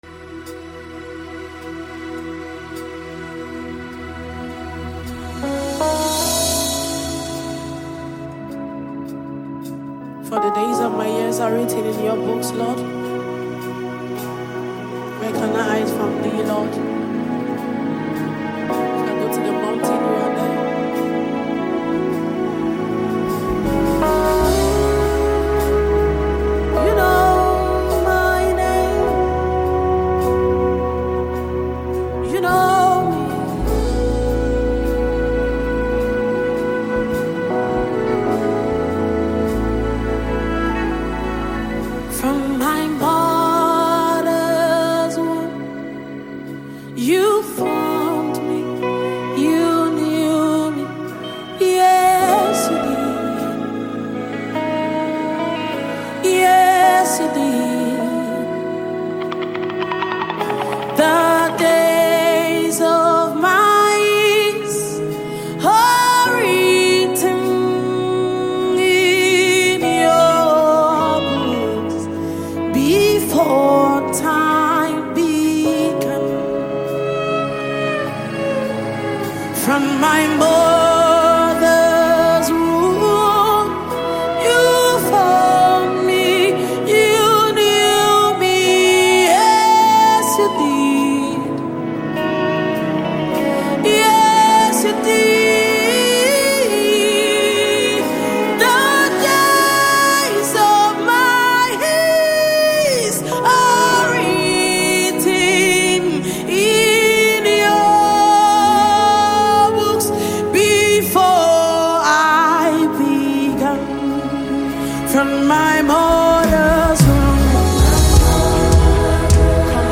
Nigerian gospel artist